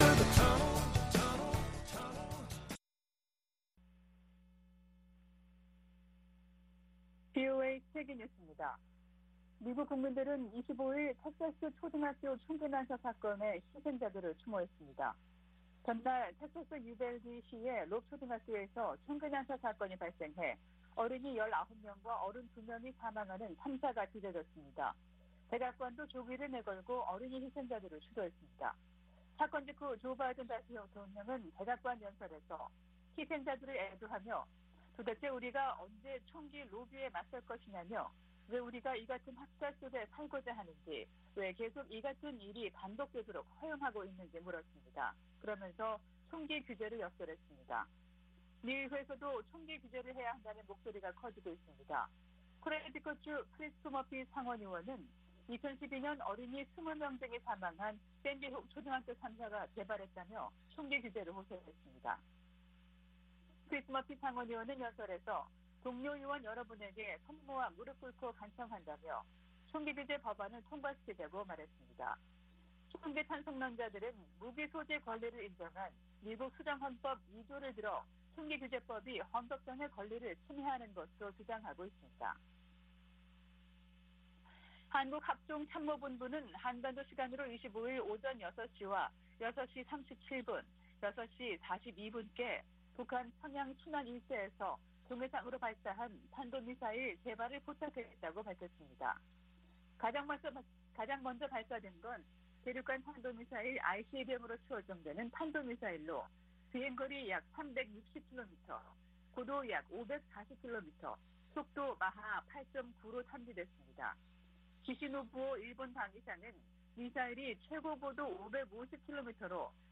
VOA 한국어 아침 뉴스 프로그램 '워싱턴 뉴스 광장' 2022년 5월 25일 방송입니다. 북한이 ICBM을 포함한 탄도미사일 3발을 동해상으로 발사했습니다. 미-한 군 당국은 미사일 실사격과 전투기 훈련 등으로 공동 대응했습니다. 미 국무부는 북한의 핵실험 등 추가 도발 가능성을 여전히 우려하고 있으며 적절한 책임을 물릴 것이라고 밝혔습니다.